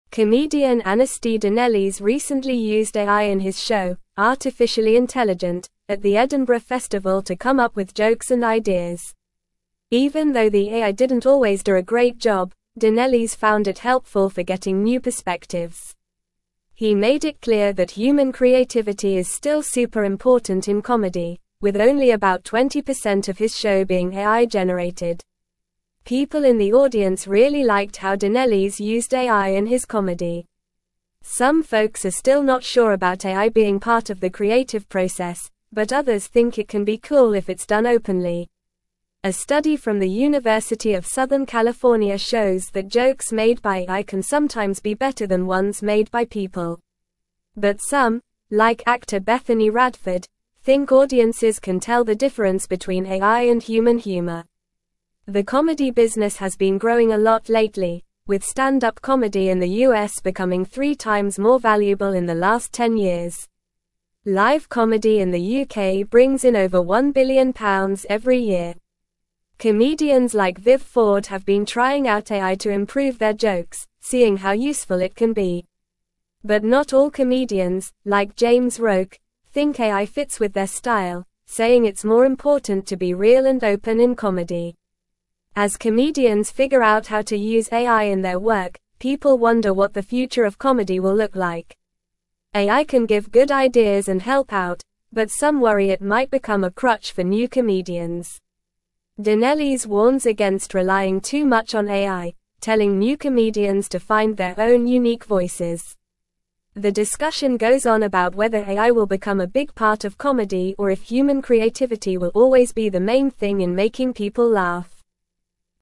Normal
English-Newsroom-Upper-Intermediate-NORMAL-Reading-Comedians-Embrace-AI-for-Joke-Writing-and-Shows.mp3